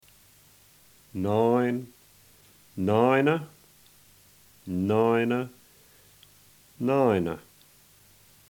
Puhoi Egerländer Dialect